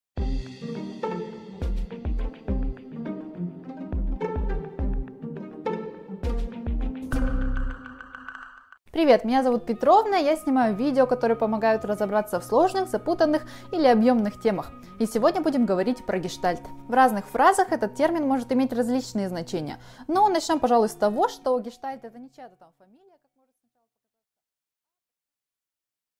Аудиокнига Что такое гештальт | Библиотека аудиокниг